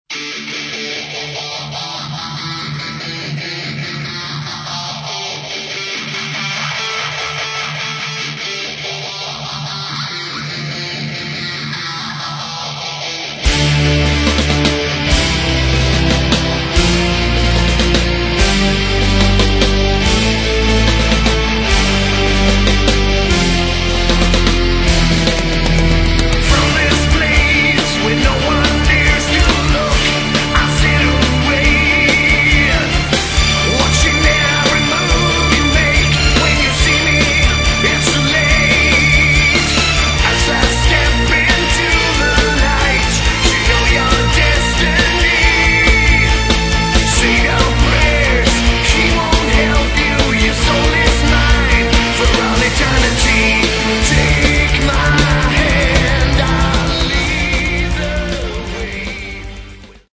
Metal
вокал, гитары
бас
клавишные
ударные